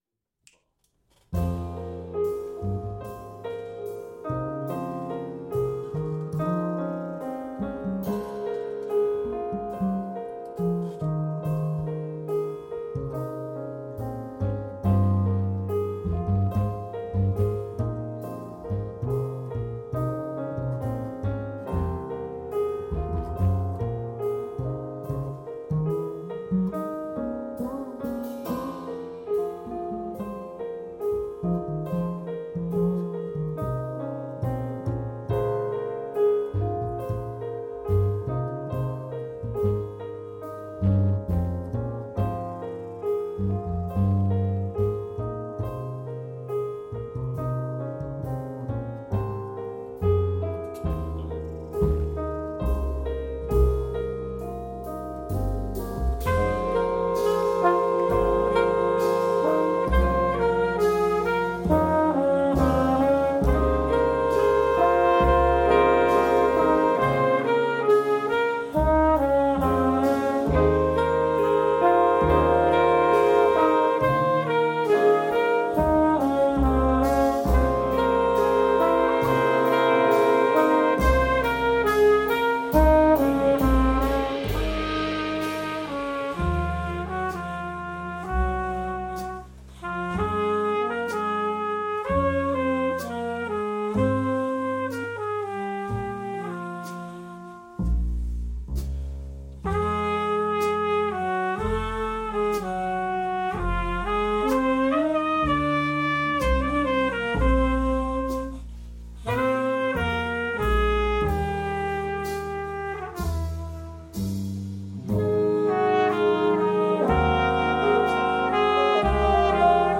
These recordings feature my playing and writing.